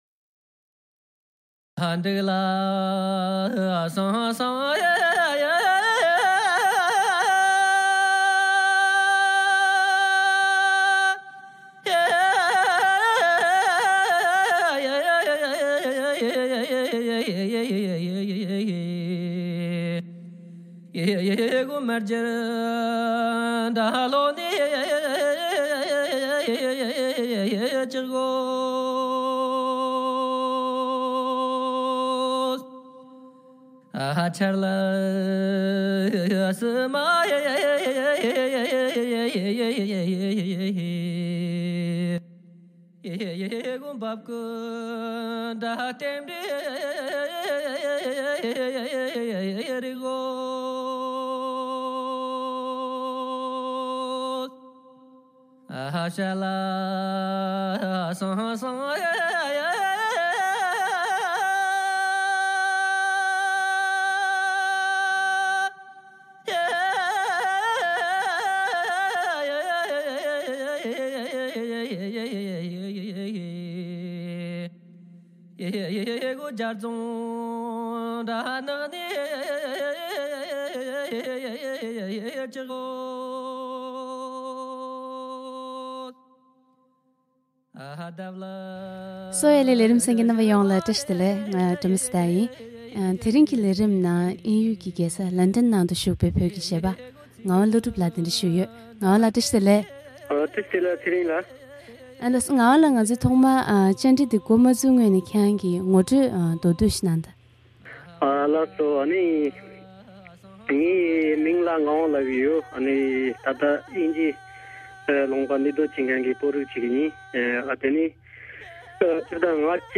who has been enthralling audiences with traditional Tibetan nomadic ballads and contemporary folk songs for the last 10 years.